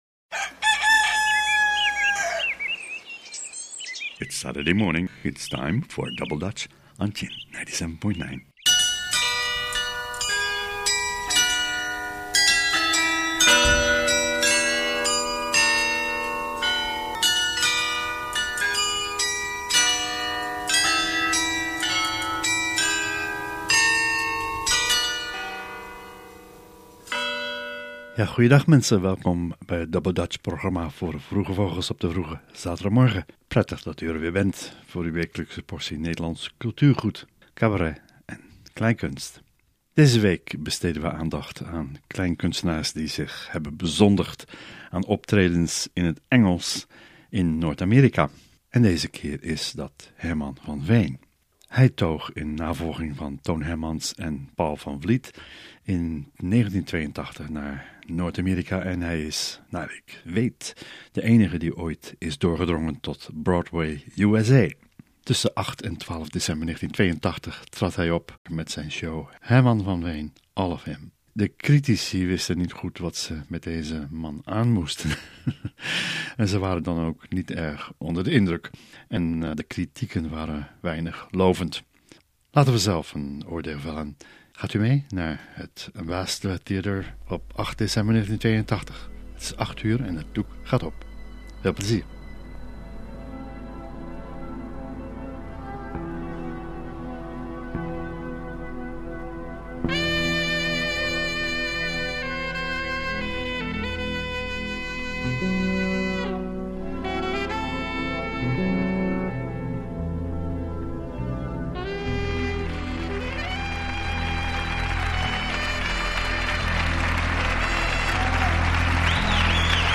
Het Nederlandse uurtje in Ottawa en contreien! Rasechte radio met liefde gemaakt voor Nederlandstalige luistervinken!